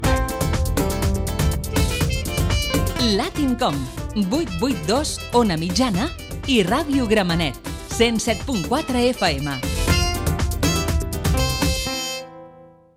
Indicatiu conjunt 882 Ona Mitjana i Ràdio Gramenet